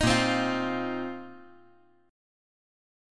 Listen to A#sus2sus4 strummed